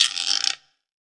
PLGUIRO.wav